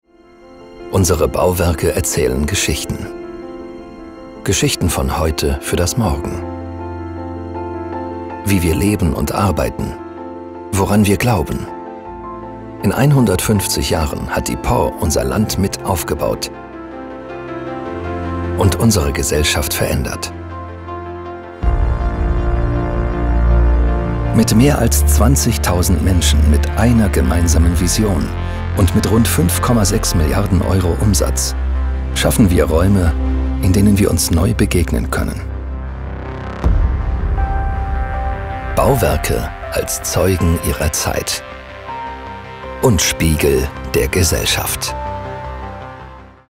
•       IMAGEFILM